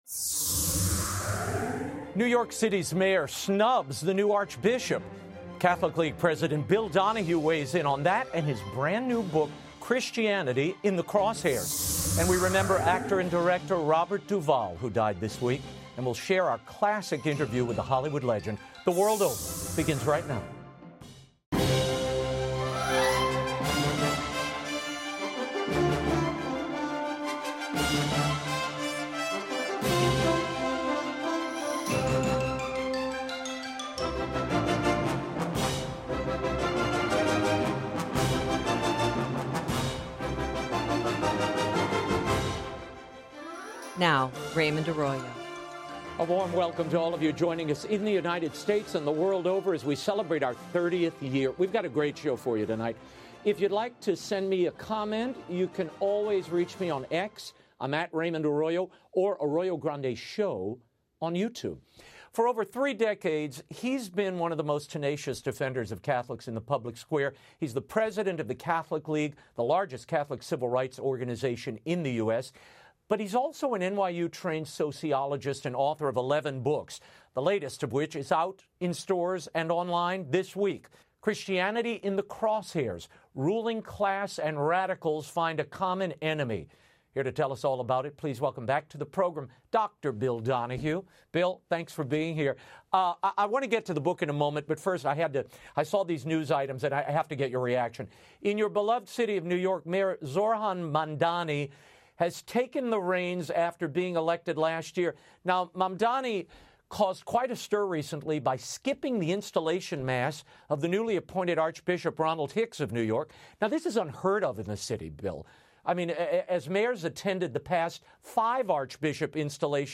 Bill Donohue discusses his new book. And, an encore presentation of our interview with Robert Duvall in memorial of his passing on February 15th.